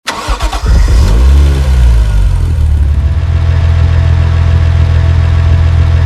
supra_idle.wav